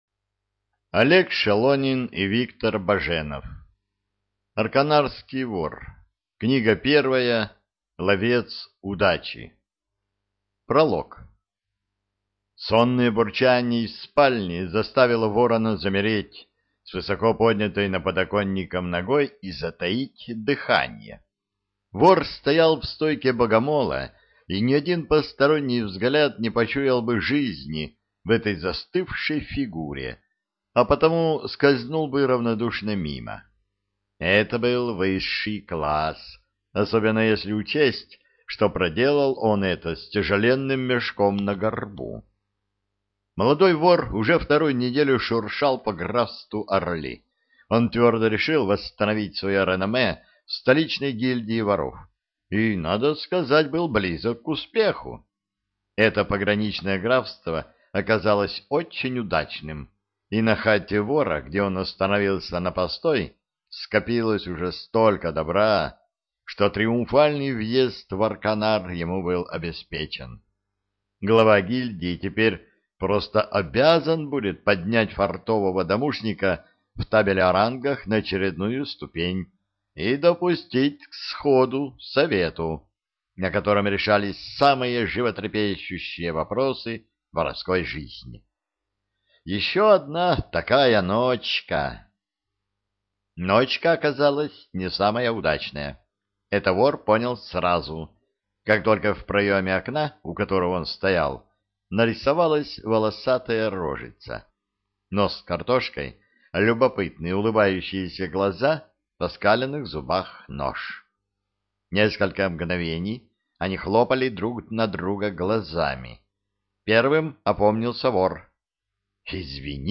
ЖанрЮмор и сатира, Фэнтези